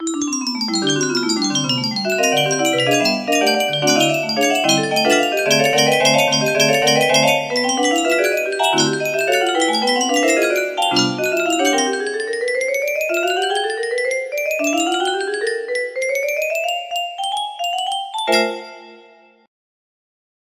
10209 music box melody